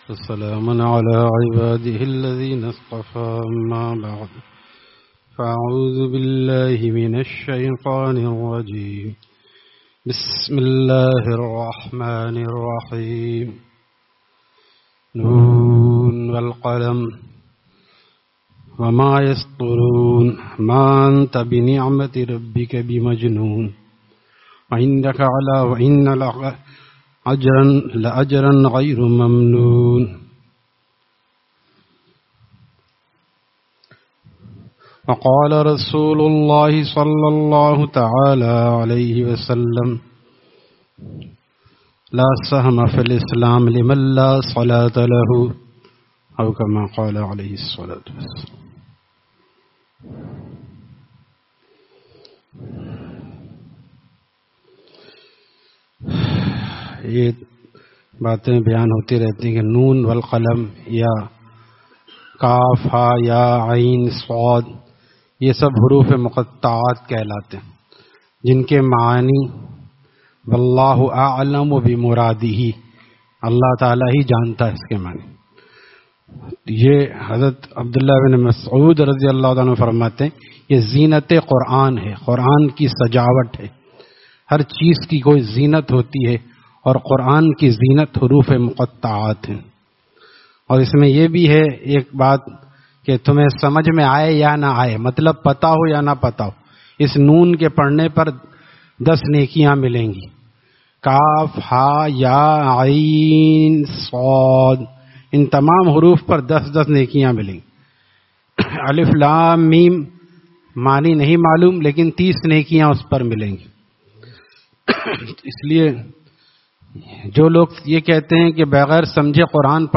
Friday Markazi Bayan at Jama Masjid Gulzar e Muhammadi, Khanqah Gulzar e Akhter, Sec 4D, Surjani Town